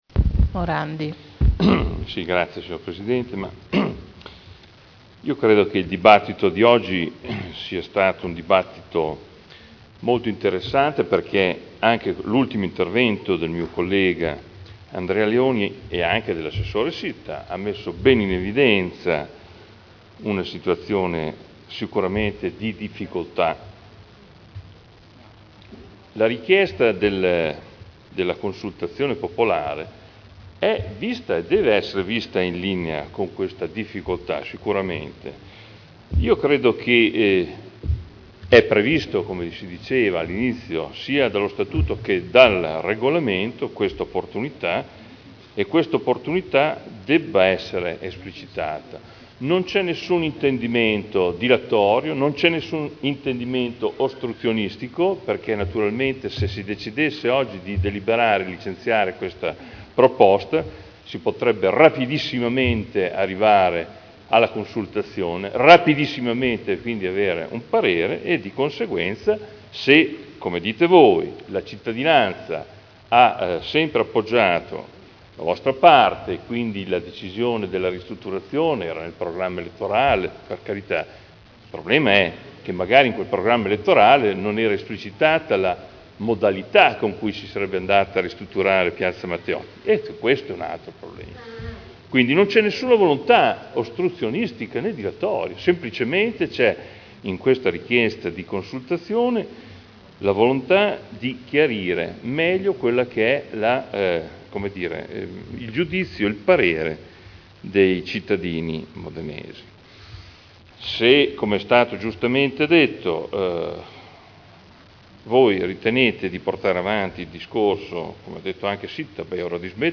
Adolfo Morandi — Sito Audio Consiglio Comunale
Replica a dibattito su delibera: Consultazione popolare ai sensi dell’art. 8 degli istituti di partecipazione del Comune di Modena sul progetto di ristrutturazione di Piazza Matteotti (Conferenza Capigruppo del 27 giugno 2011 e del 4 luglio 2011)